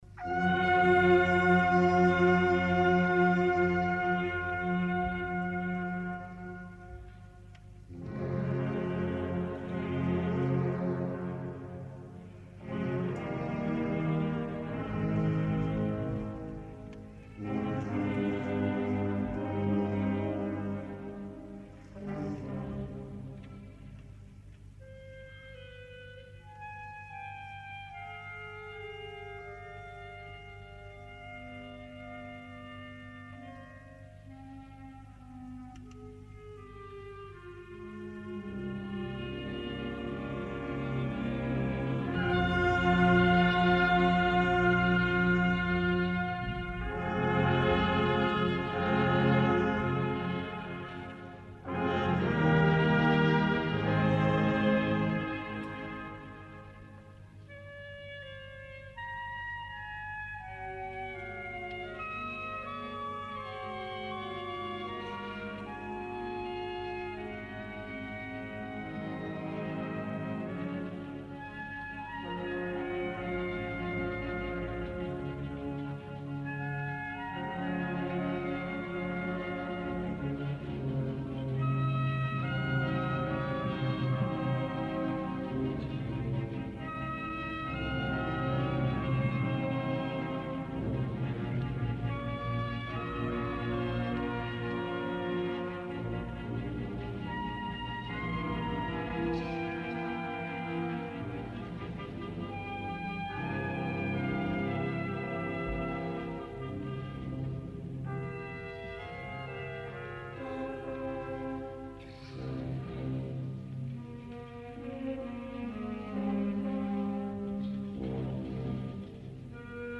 Concert de Sa Fira a l'Esglèsia de la Nostra Senyora de la Consolació